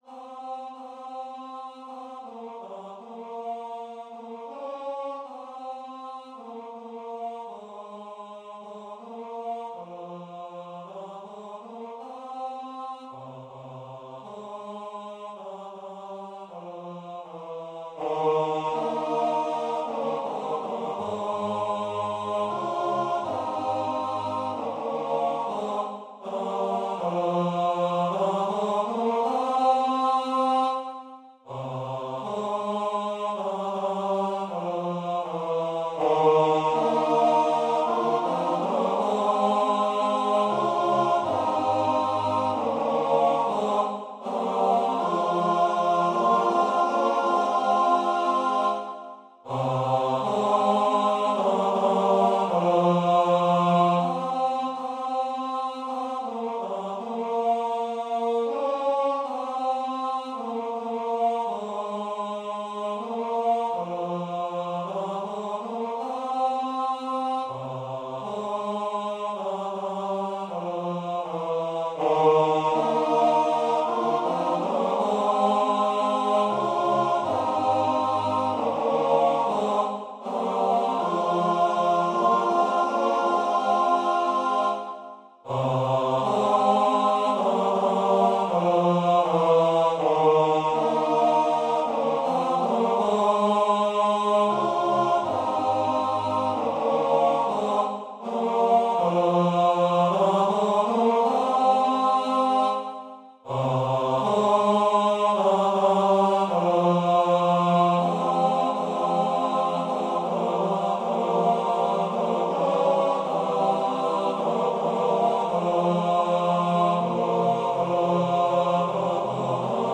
Ноты, хор, партитура голосов.
*.mid - МИДИ-файл для прослушивания нот.